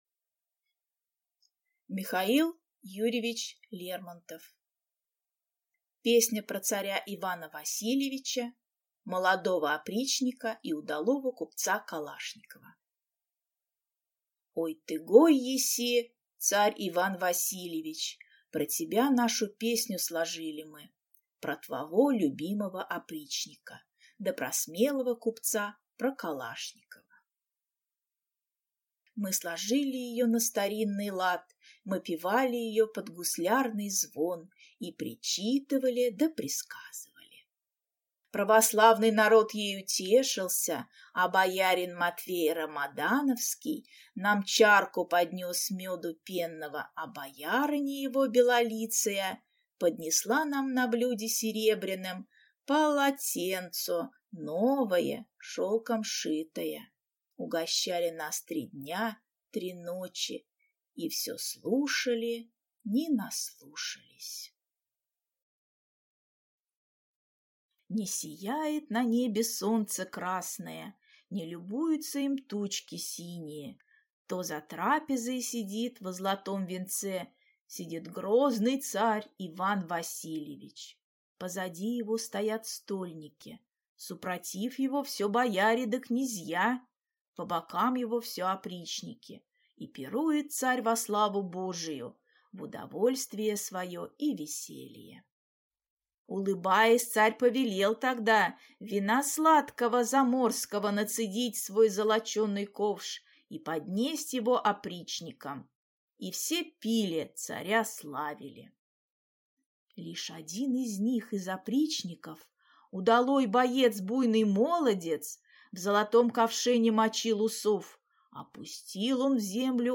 Аудиокнига Песня про царя Ивана Васильевича, молодого опричника и удалого купца Калашникова | Библиотека аудиокниг